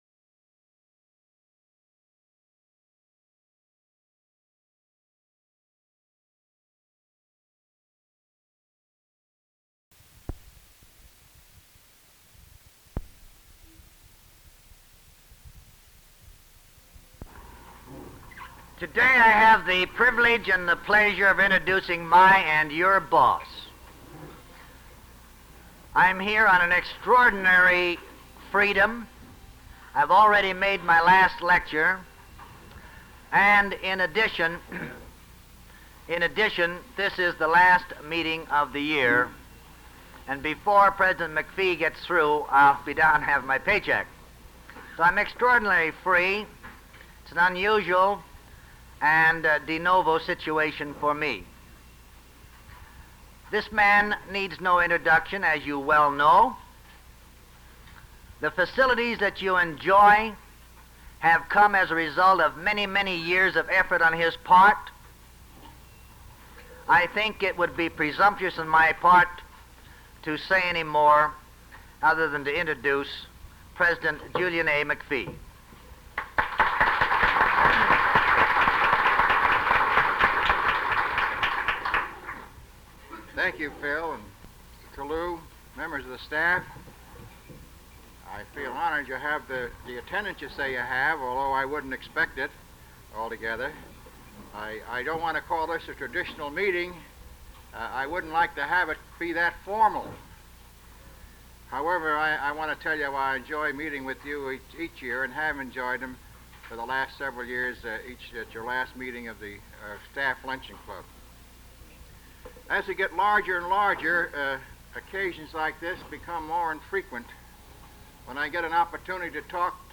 Documentation of the Cal Poly president, Julian McPhee, discussing the relationship between colleges in the California State Colleges (CSC) system and the CSC Chancellor, during a time of transitio...
Applause, McPhee thanks staff
McPhee explains why he enjoys speaking to the staff in an informal session
McPhee continues reading a speech about self-reflection on the part of the teachers and analysis to create a good learning experience